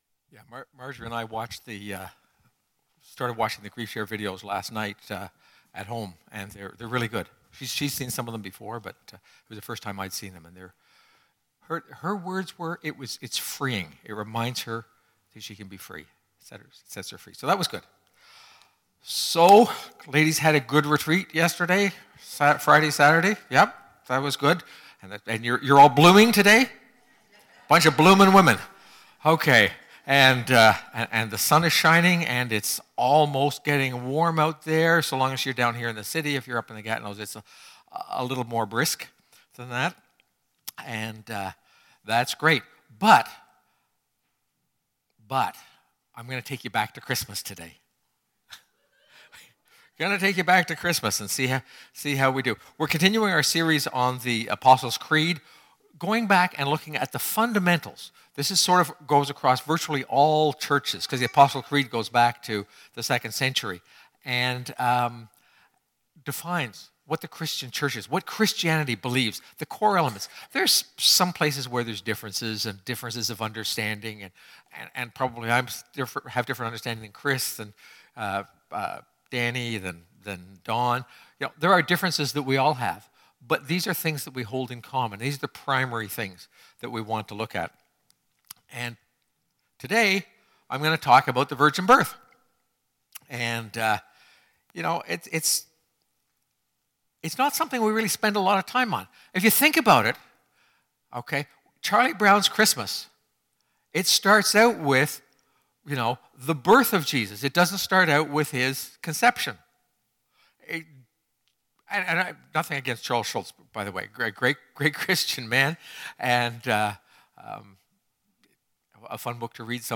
2017 Sermons